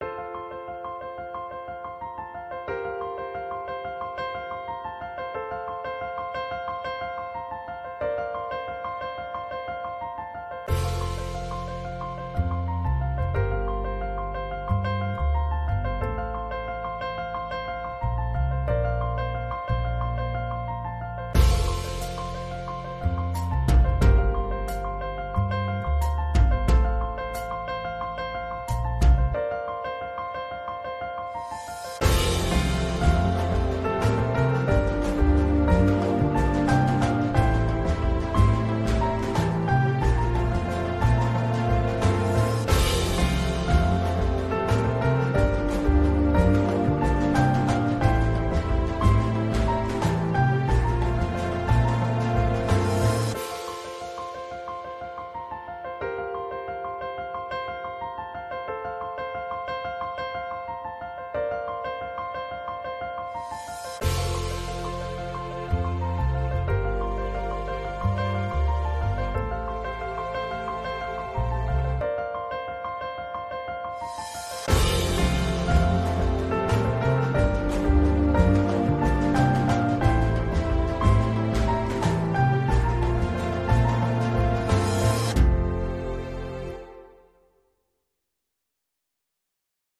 Orchestra_0329_2.mp3